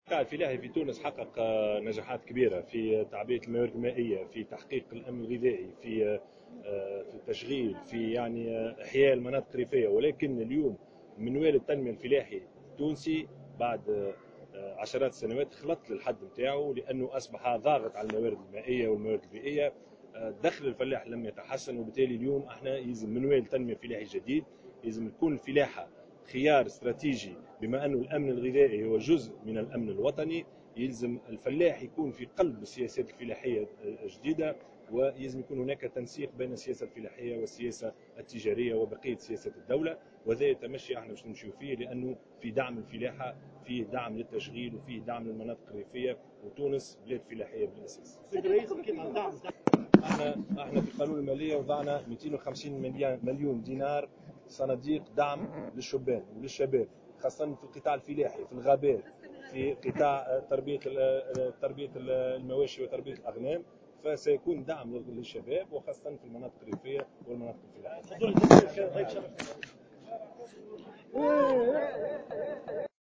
وأضاف في تصريحات صحفية على هامش افتتاحه لصالون "سيات"، إن هذه الصناديق ستخصّص بالأساس للقطاع الفلاحي في مجال الغابات وتربية المواشي والأغنام والمناطق الريفية.